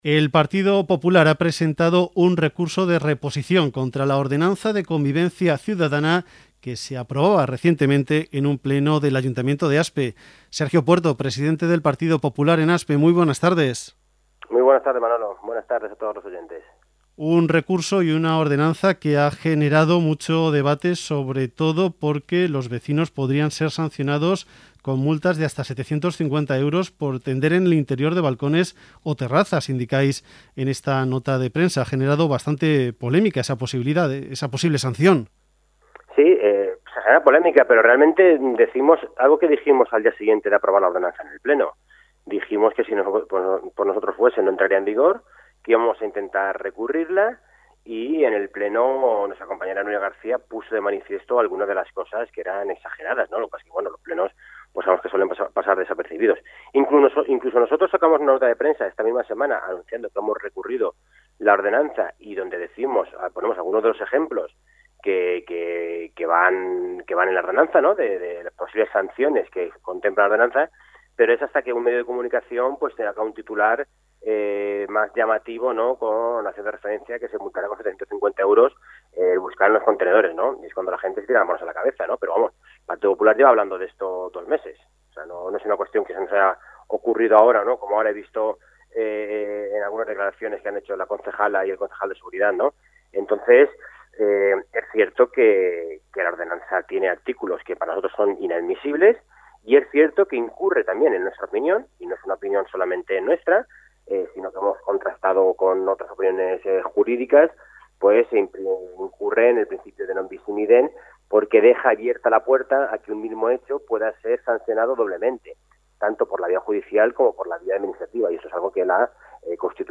ha explicado este lunes en el informativo Valle de las Uvas de Radio Aspe el recurso de reposición presentado por el PP contra la Ordenanza de Convivencia Ciudadana aprobada inicialmente en el pleno del pasado 30 de septiembre.